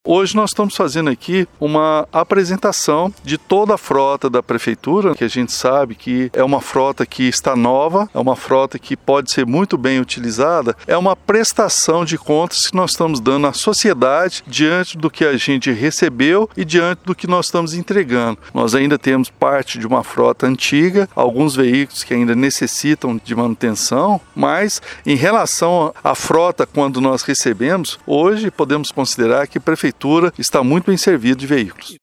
Falando ao Jornal da Manhã, Elias destacou que essa atividade é uma forma de prestação de contas para a população dos investimentos feitos pelo executivo, como forma de melhorias para a prestação de serviços: